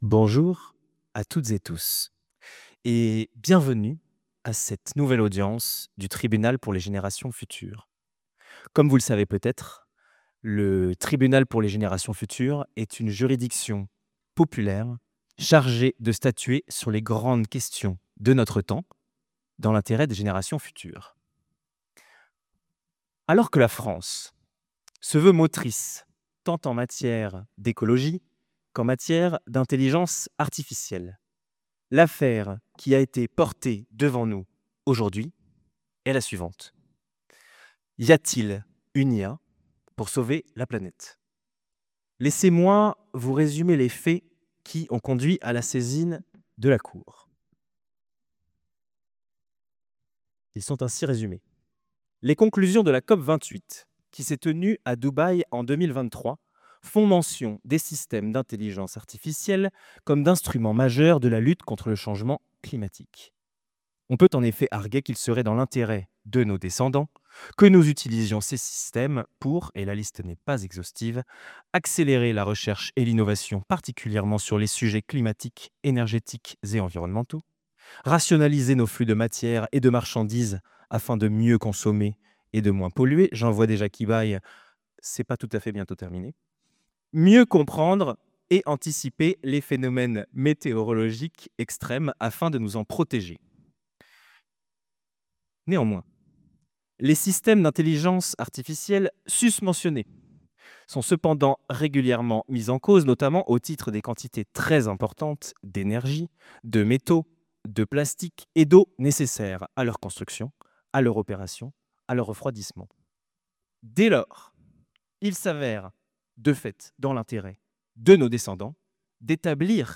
Trois jours avant l’ouverture du Sommet pour l’action sur l’IA, nous vous donnons rendez-vous ce soir à 19h00, pour assister à un Tribunal pour les générations futures sur les enjeux de justice climatique et intelligence artificielle. Vidéo de 1h24 minutes, du vendredi 7 février 2025 à la Sorbonne, Paris :
Un procès fictif pour un débat bien réel